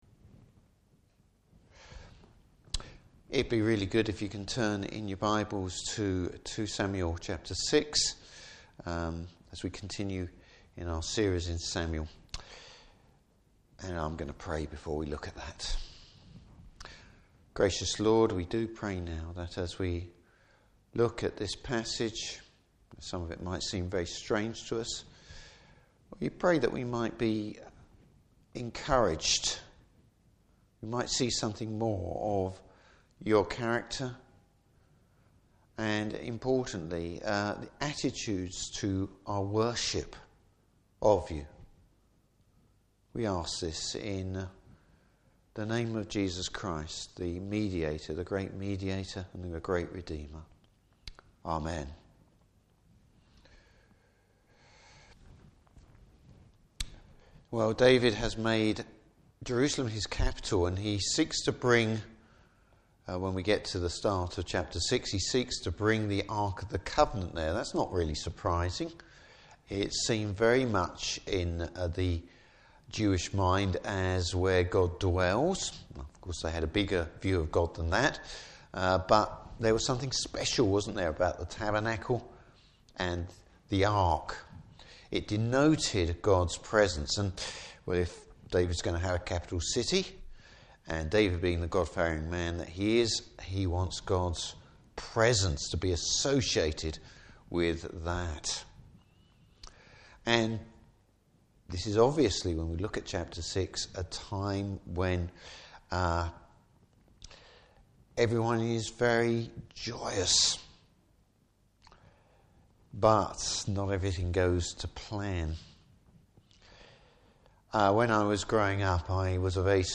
Service Type: Evening Service A scary lesson on the nature of God.